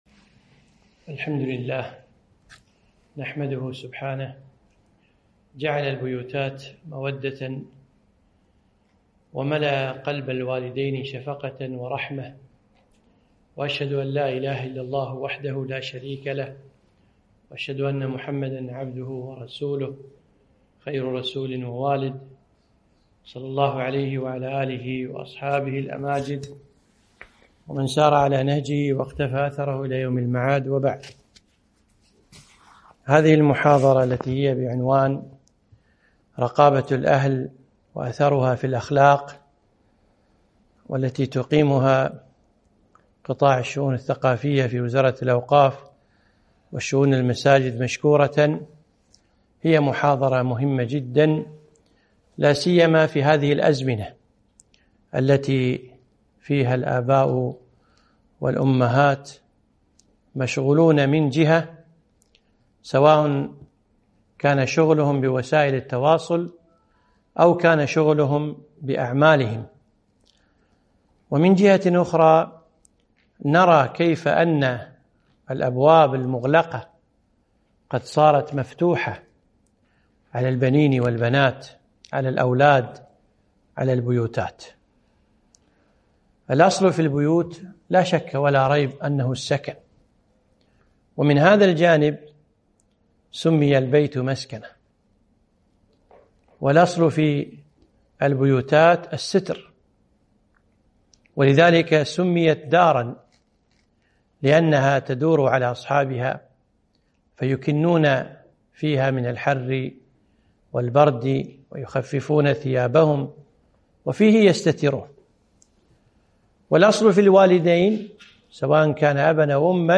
محاضرة - رقابة الأهل وأثرها في الأخلاق